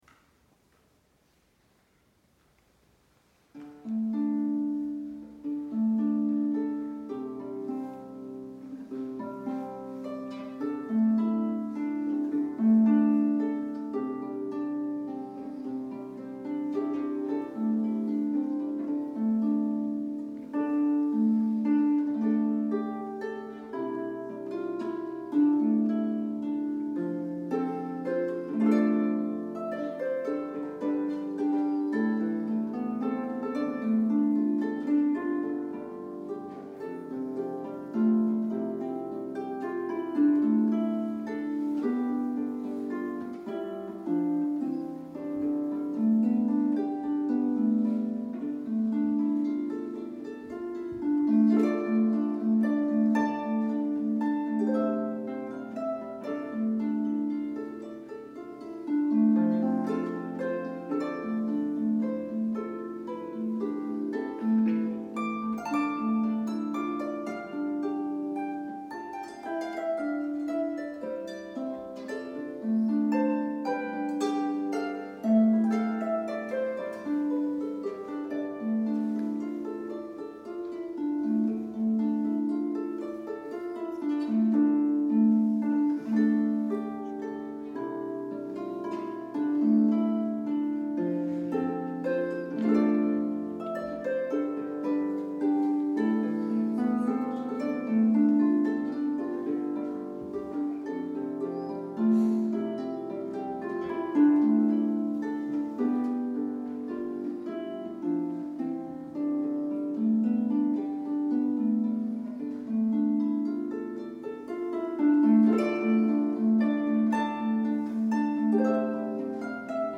• Harfe | Klavier
Scarborough Fair - Trad. (live in der Kirche Elmshorn, Nov. 2022):